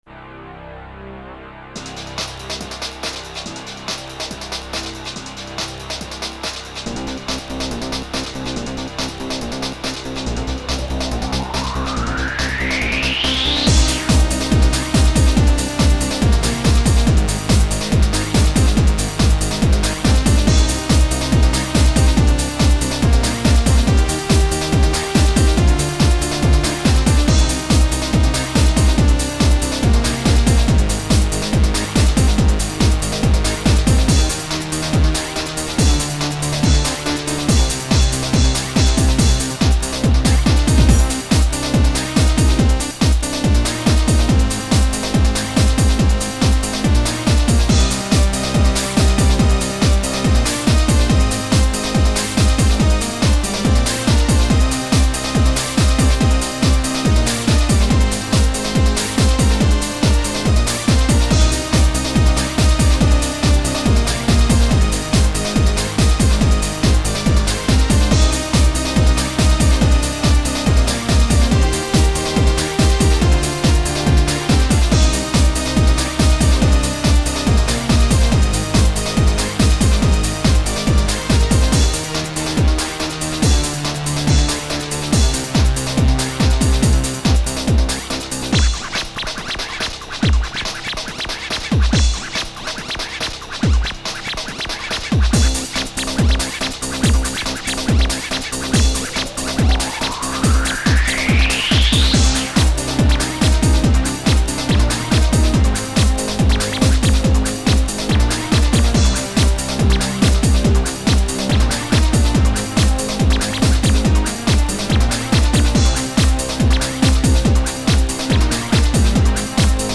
• Quality: 44kHz, Stereo